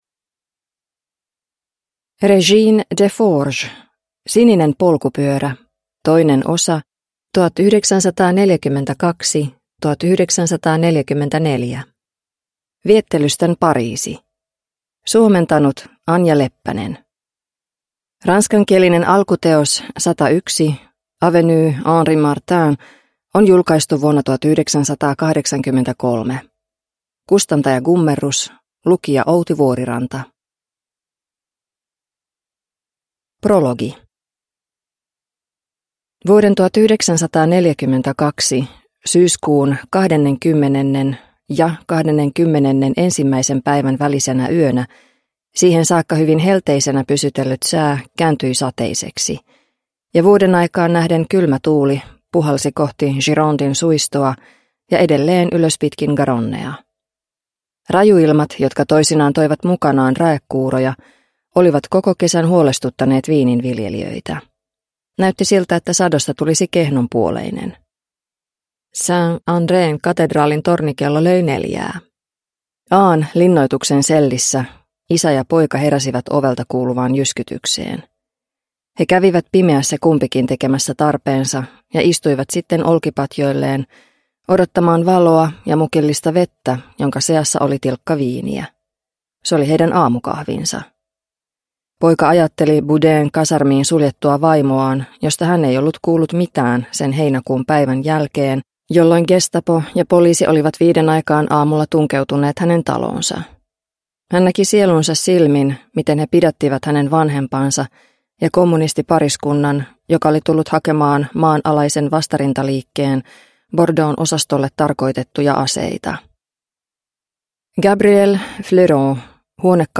Romanttisen jännityskirjallisuuden klassikko vihdoinkin äänikirjana!